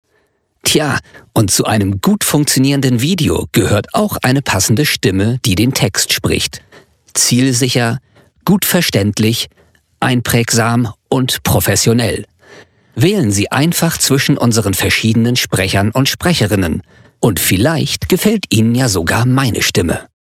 Anhang anzeigen 21807 Da kannst du halt hören, wieviel Raum in der Aufnahme ist und ob der sich bei viel Kompression negativ auswirkt.